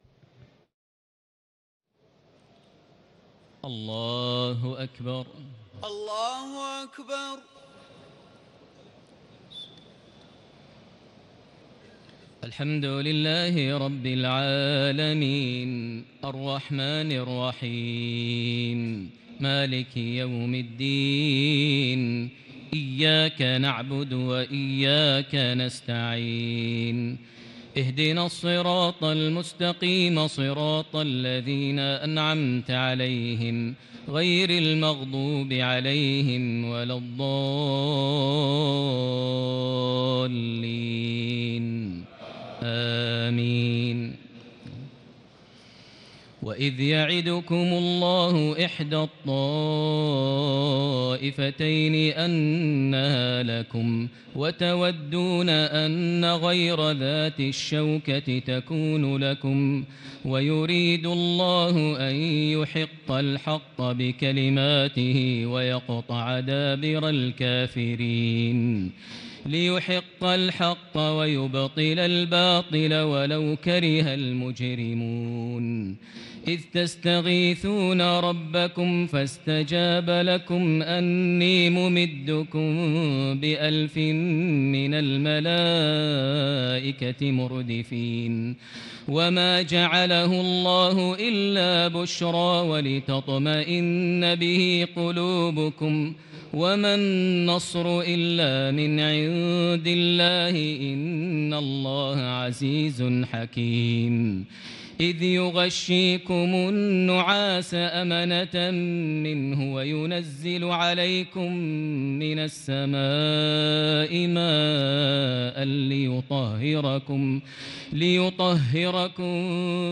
تهجد ليلة 29 رمضان 1439هـ من سورة الأنفال (7-40) Tahajjud 29 st night Ramadan 1439H from Surah Al-Anfal > تراويح الحرم المكي عام 1439 🕋 > التراويح - تلاوات الحرمين